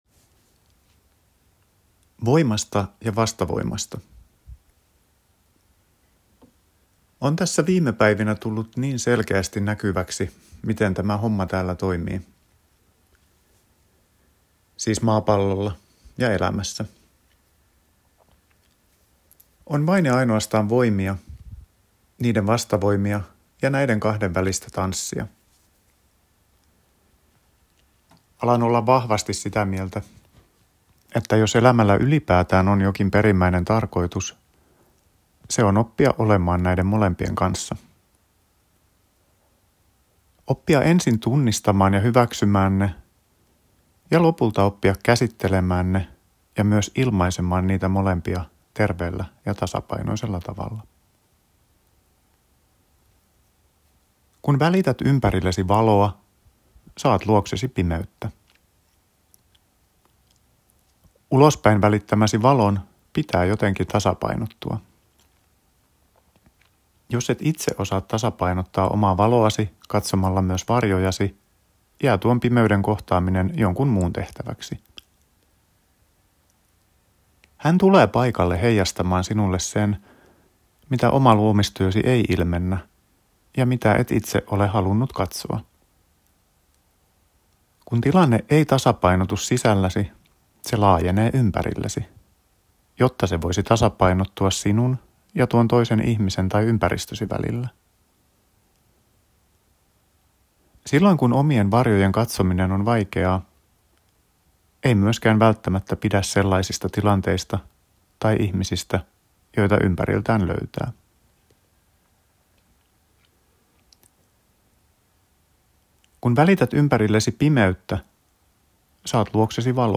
Kirjoitus luomisvoimasta, luovuudesta ja tasapainosta. Voit myös kuunnella tekstin lukemanani.